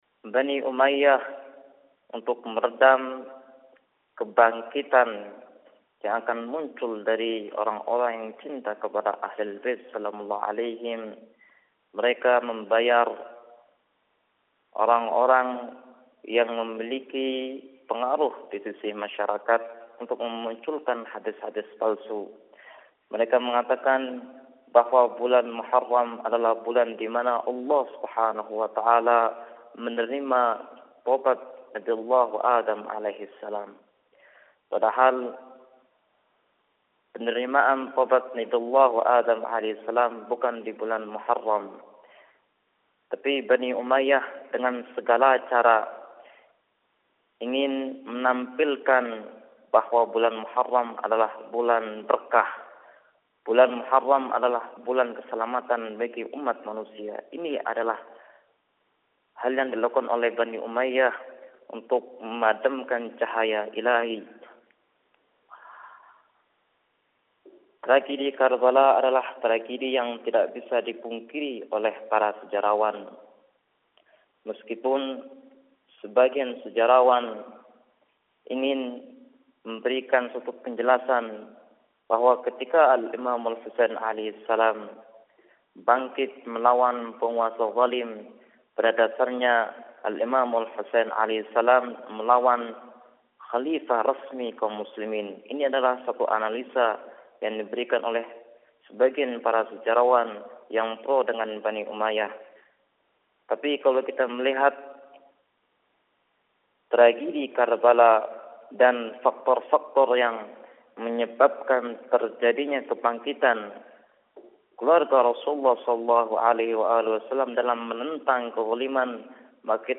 Ceramah Asyura